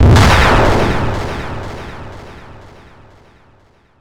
snd_explosion.wav